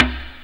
prcTTE44018tom.wav